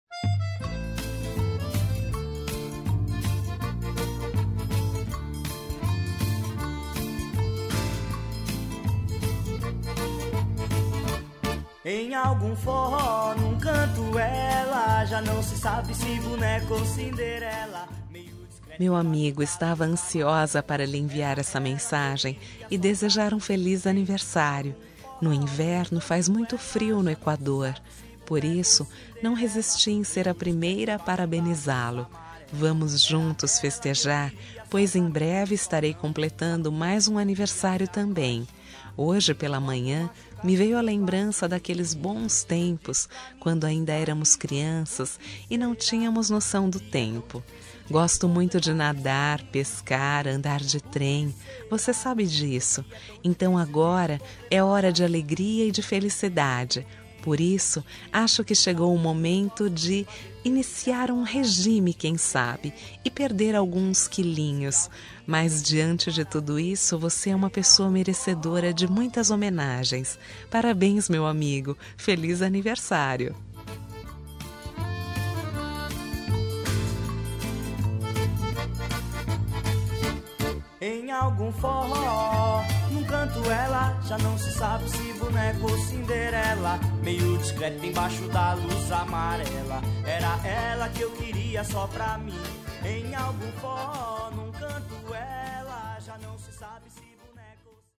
Aniversário de Humor – Voz Feminina – Cód: 200105